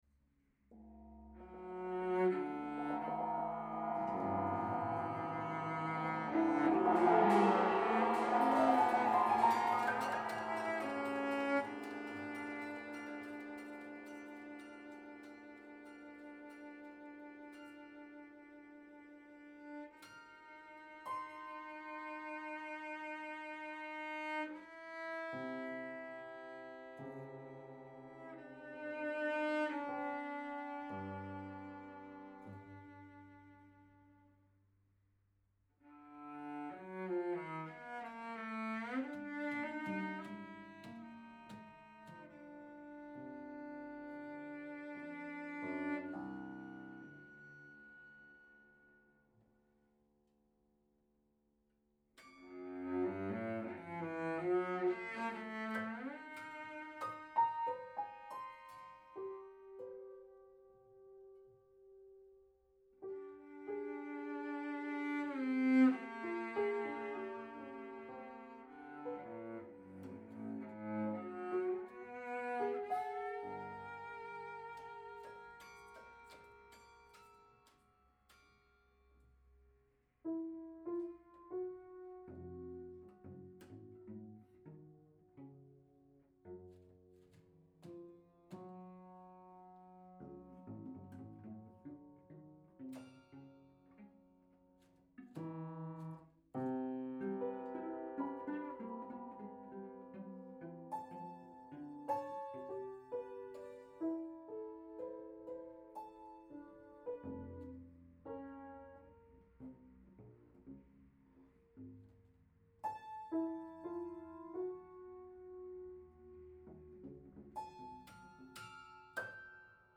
improvisation duo
What we do know for certain, though, is that we improvise.